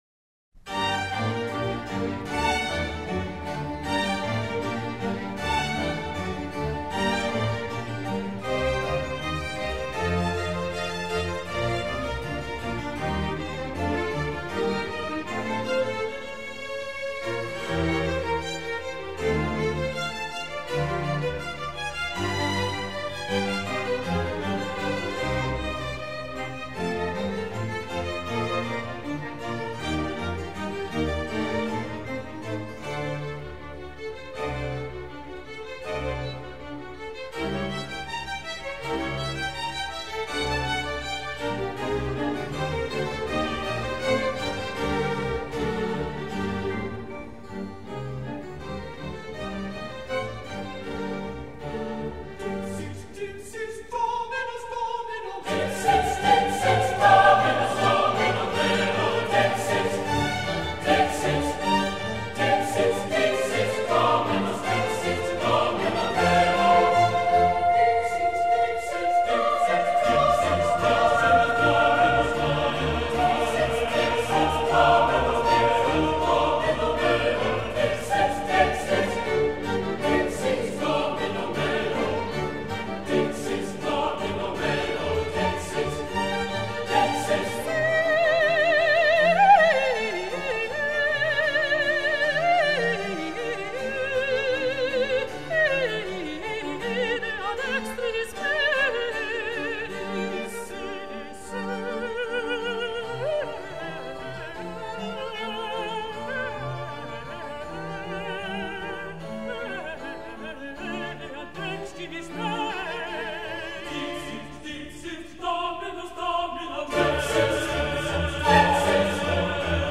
演出者 : David Willcocks 大衛．維爾確斯 / 指揮
劍橋國王學院合唱團、英國室內樂團
此曲是以四位獨唱家加上合唱團、管絃樂團為對象寫成的，此曲韓德爾採用的是定旋律的作曲手法，由此發展成幾闕充滿氣勢的對位合唱，再合唱之間插入幾段獨唱詠歎調或重唱。